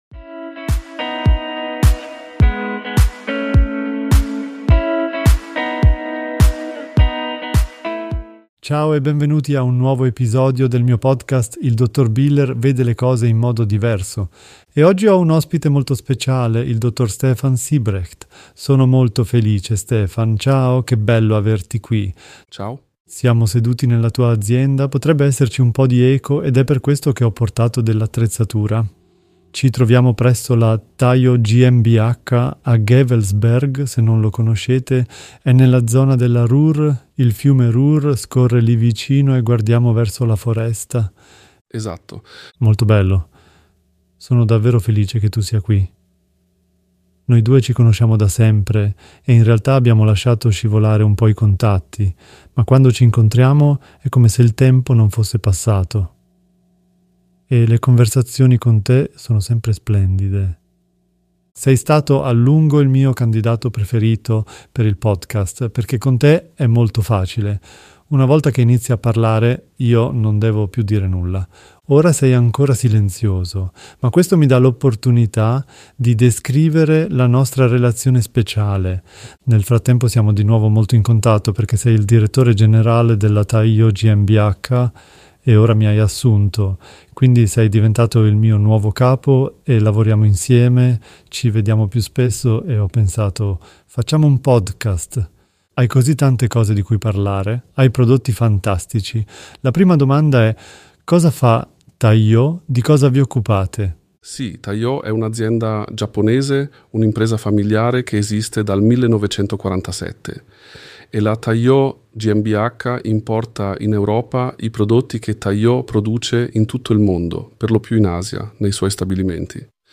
Beschreibung vor 4 Monaten Questa è la traduzione assistita dal Ki del 31° episodio